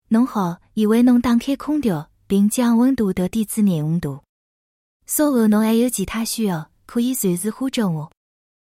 多年来，微软持续探索 AI 语音合成与识别技术，获得了大量成果：Azure Neural TTS（text-to-speech，语音合成）与 STT（speech-to-text，语音识别）支持的语言区域达到 140 余个，并支持超过 400 多个音色，并具有丰富细腻的情感，与真人声音不相伯仲。
吴方言（上海）
吴方言（上海）.mp3